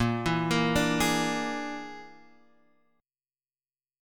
A#7b5 Chord